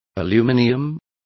Complete with pronunciation of the translation of aluminium.